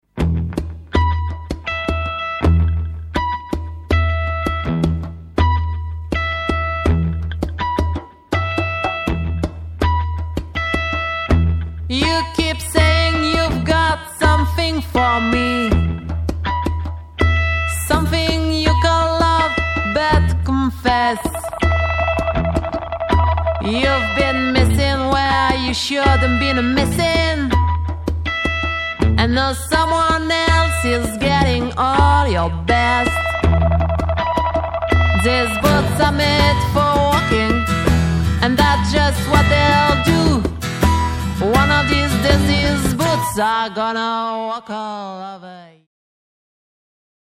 qui correspond plus au style pop-rock